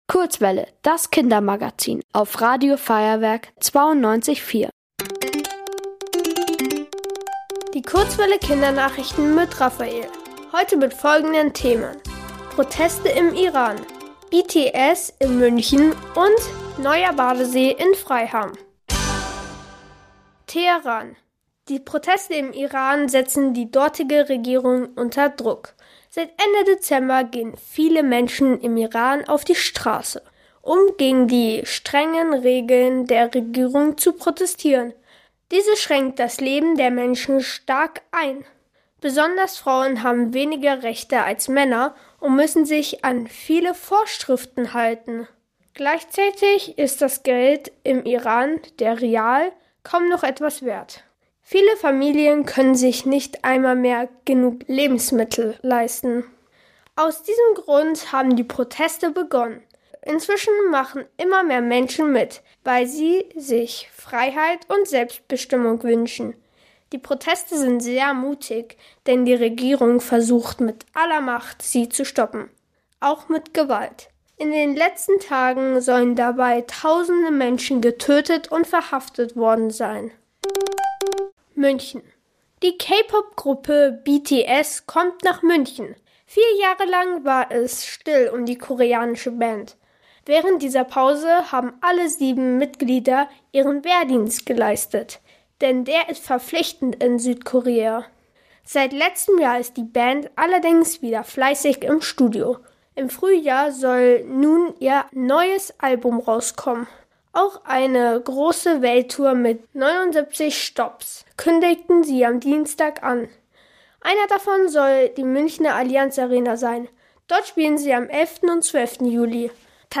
Hier gibt's die Kindernachrichten für Euch